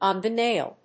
on the náil